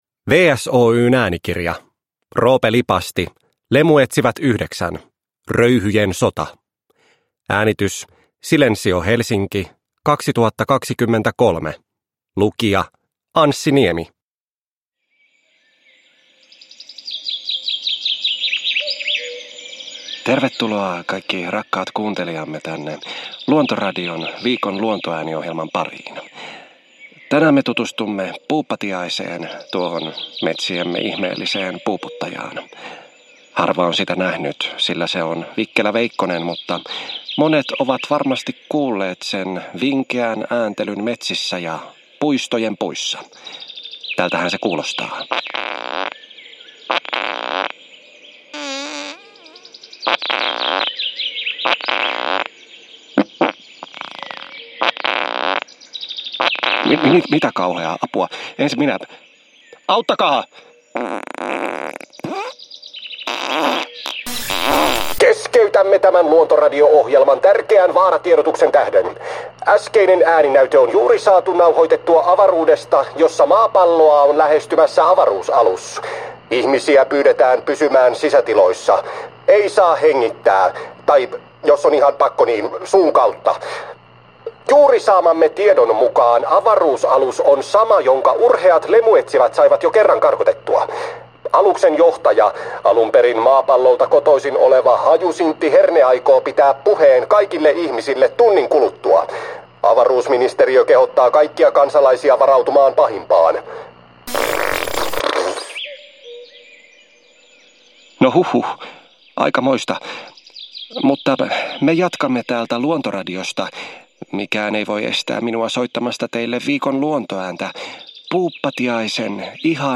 Lemuetsivät 9: Röyhyjen sota – Ljudbok – Laddas ner